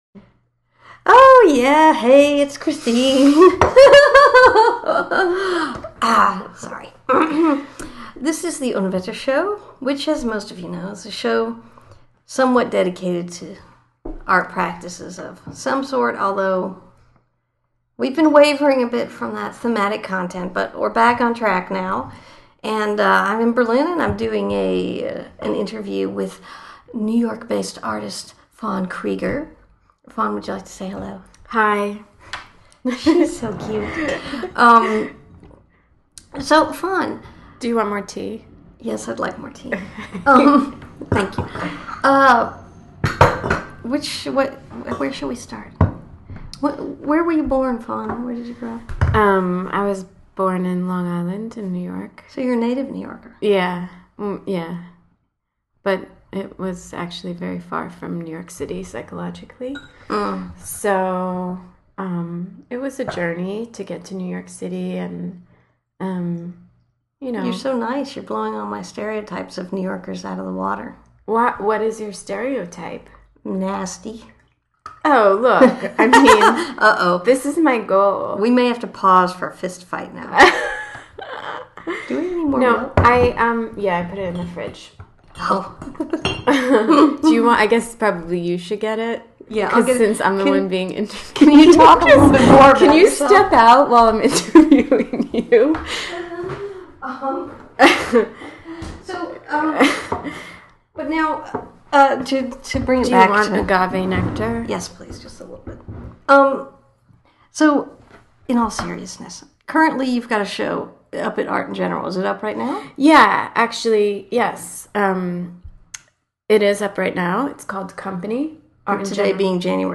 Pirate Cat Radio interview